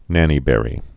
(nănē-bĕrē)